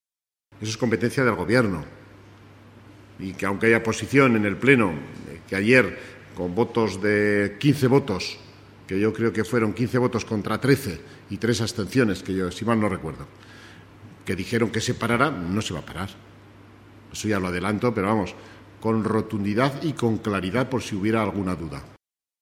Además, sobre la posibilidad de que se paralice el proyecto de la segunda línea de tranvía, Fernando Gimeno ha manifestado: